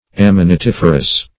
Search Result for " ammonitiferous" : The Collaborative International Dictionary of English v.0.48: Ammonitiferous \Am`mon*i*tif"er*ous\, a. [Ammonite + -ferous.] Containing fossil ammonites.
ammonitiferous.mp3